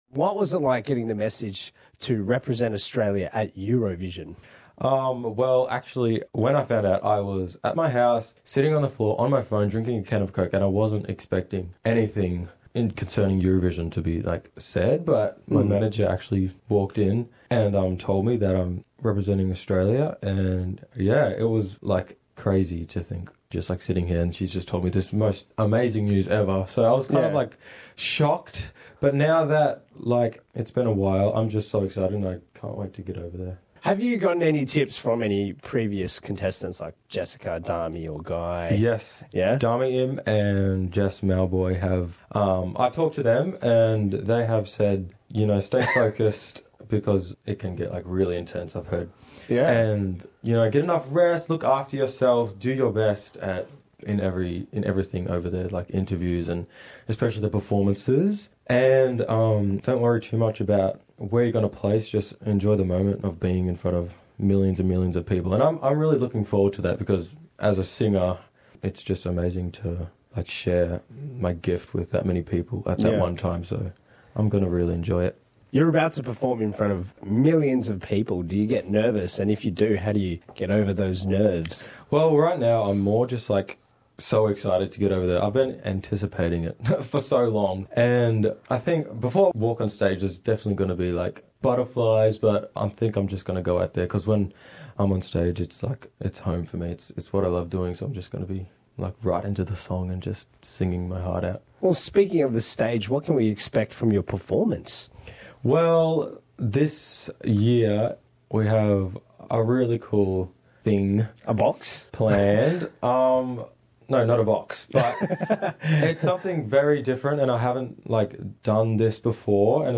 Interviu cu Isaiah Firebrace inaintea Eurovizionului 2017